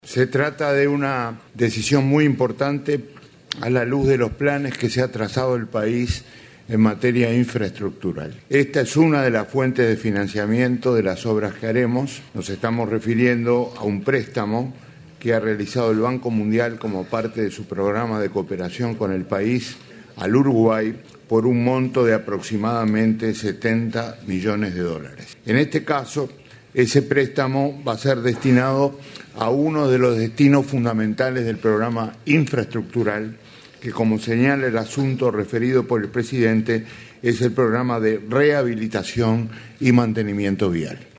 “Uruguay recibió un préstamo de 70 millones de dólares del Banco Mundial para infraestructura vial”, anunció el ministro de Economía, Danilo Astori, este lunes en el Consejo de Ministros abierto de Montevideo. El préstamo tendrá como destino el programa de rehabilitación y mantenimiento de rutas, caminos y puentes, que incorpora componentes de seguridad del tránsito y mitigación del impacto de los efectos climáticos.